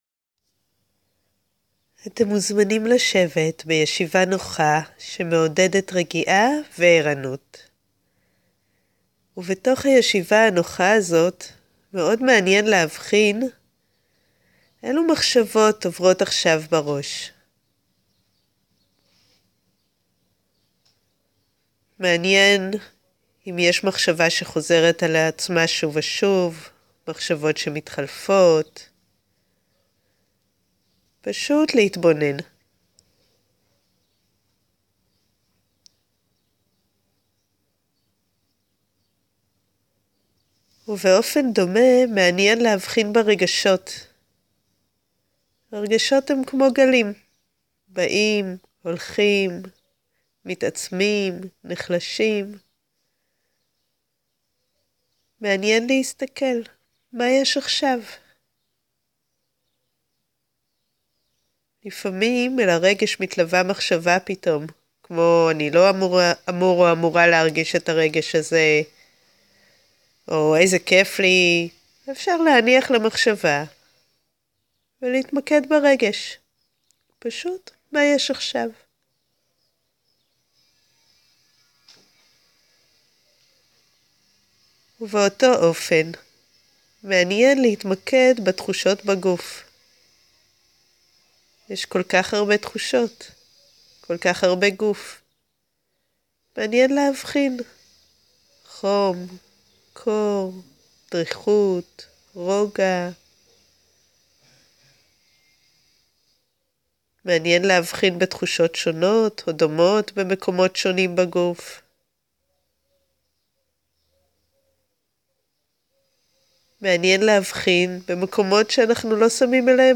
מדיטציית מיינדפולנס-חמש דקות של רוגע